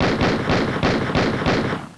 multiplegunshots.au